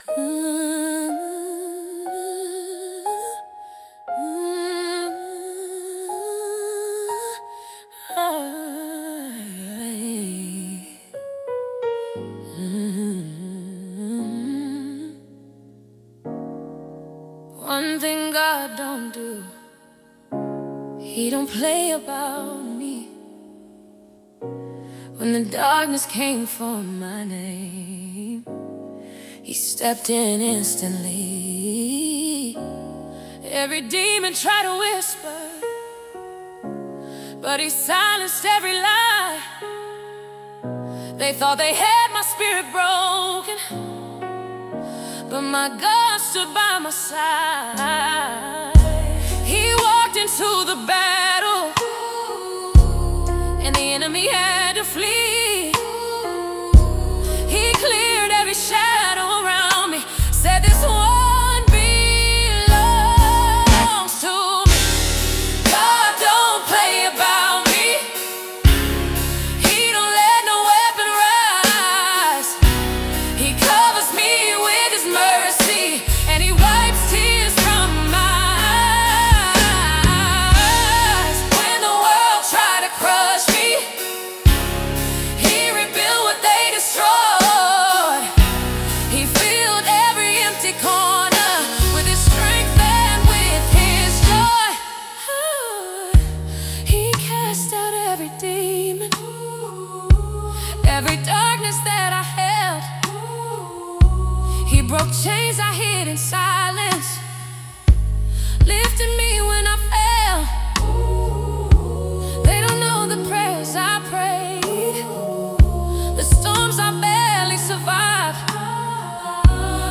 creating an uplifting and powerful energy.